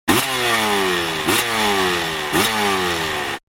جلوه های صوتی
دانلود صدای موتور 12 از ساعد نیوز با لینک مستقیم و کیفیت بالا
برچسب: دانلود آهنگ های افکت صوتی حمل و نقل دانلود آلبوم صدای موتورسیکلت از افکت صوتی حمل و نقل